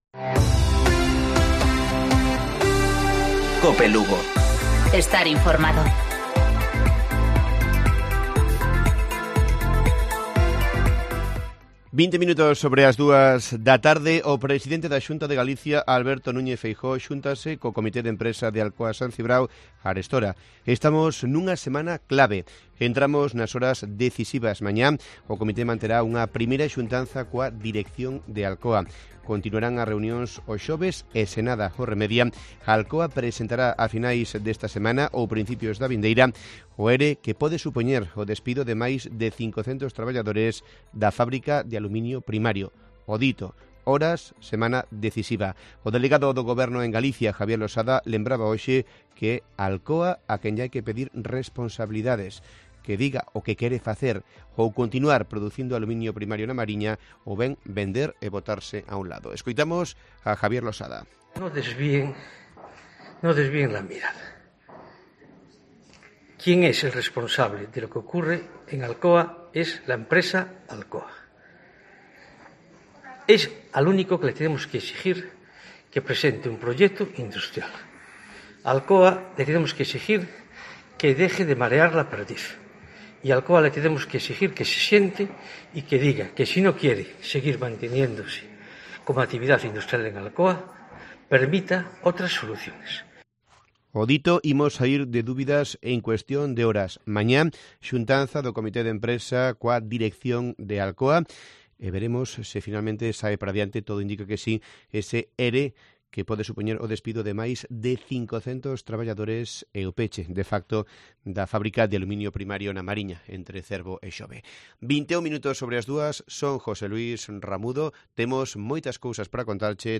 Informativo Mediodía Cope.